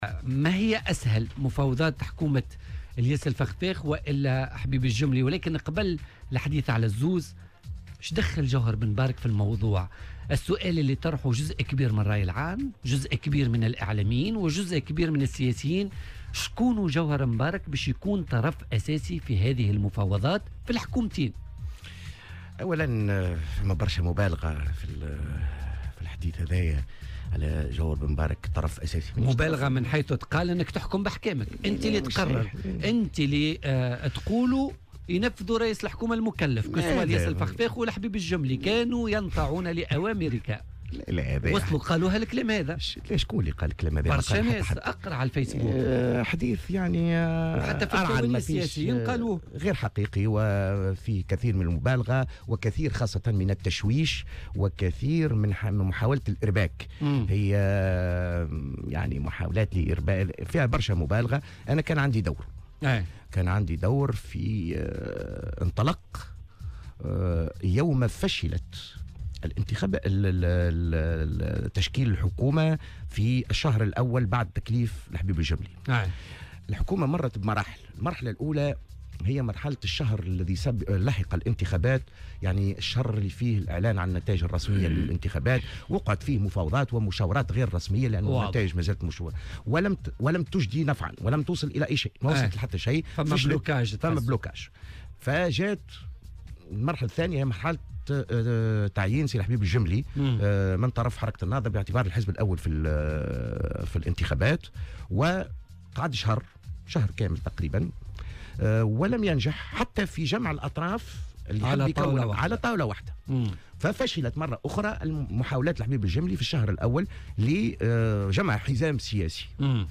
وأضاف في مداخلة له اليوم في برنامج "بوليتيكا" أن دوره انطلق عندما فشلت محاولات تشكيل الحكومة في الشهر الأول من تكليف الحبيب الجملي، موضحا أنه تقدّم بمبادرة من أجل حلحلة الوضع وتقريب وجهات النظر، خاصة وأن لديه علاقات مع مختلف الأحزاب ويشتغل في الحقل السياسي منذ سنوات، وفق تعبيره.